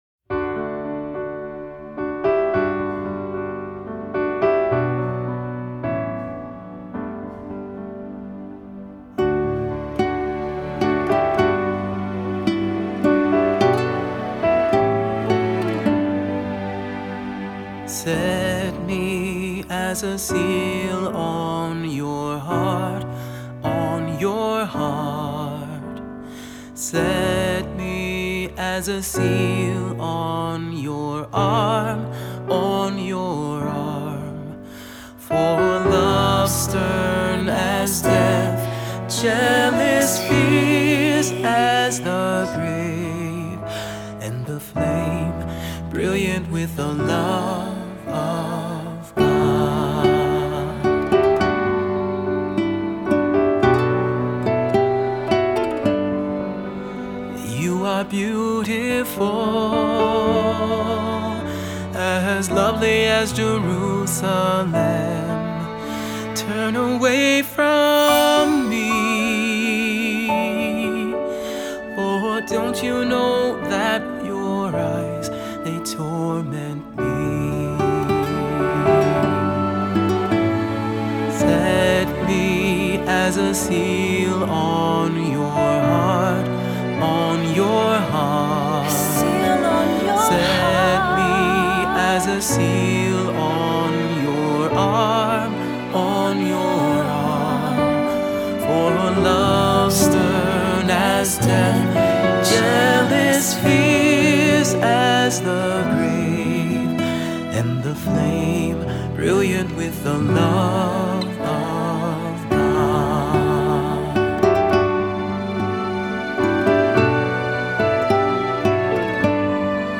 Voicing: Unison; Two-part equal; Cantor